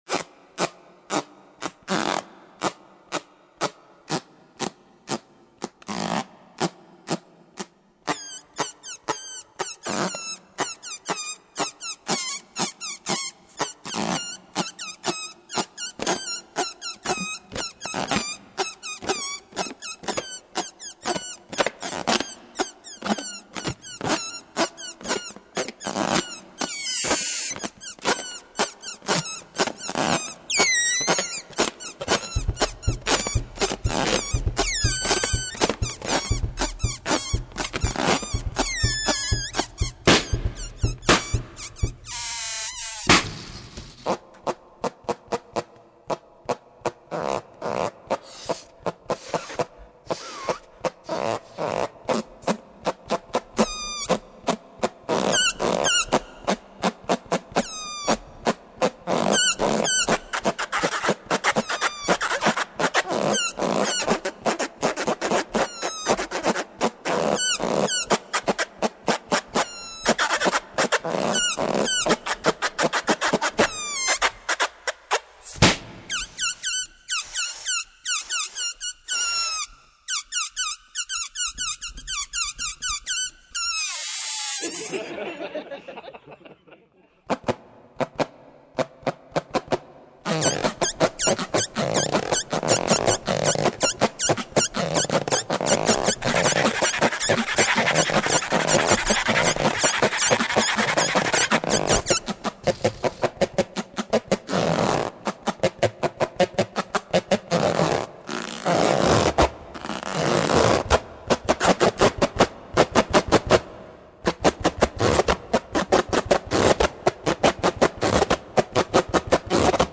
Besetzung: Instrumentalnoten für Schlagzeug/Percussion
is a fun mix of percussion, theater, and balloons!